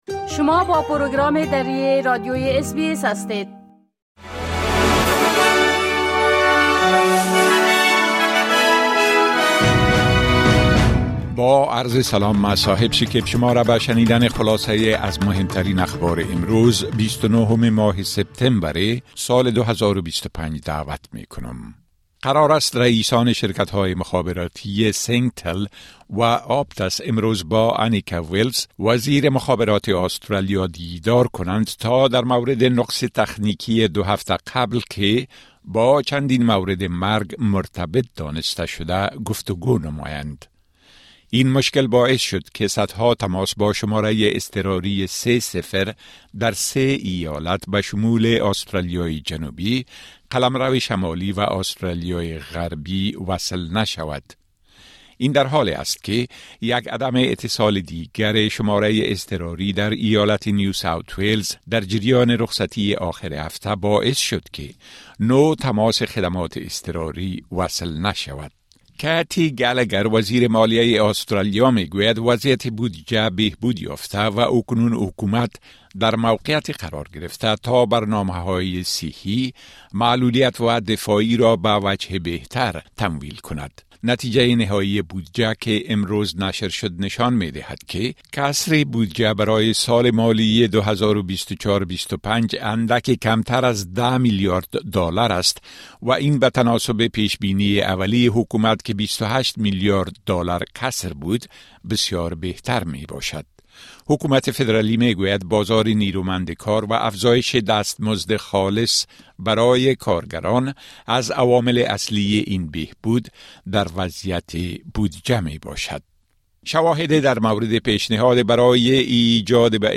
خلاصه مهمترين خبرهای روز از بخش درى راديوى اس‌بى‌اس